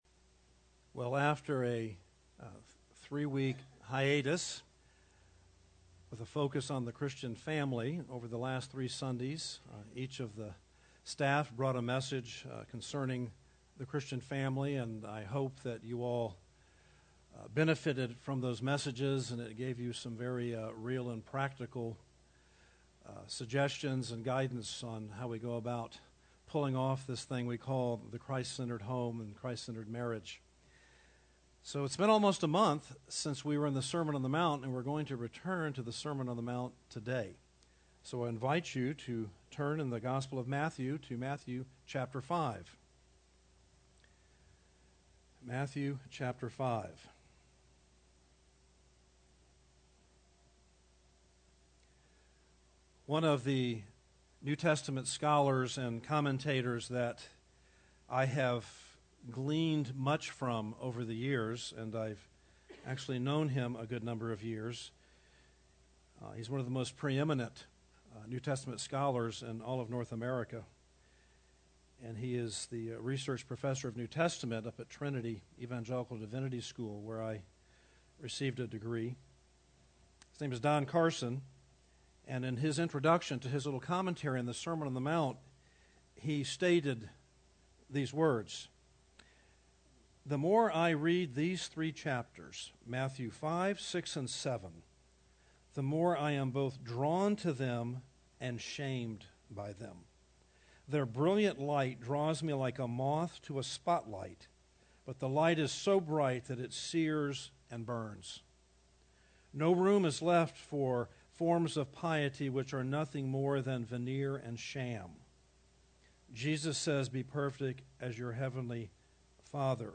Series: Jesus' Sermon on the Mount